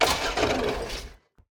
car-no-fuel-1.ogg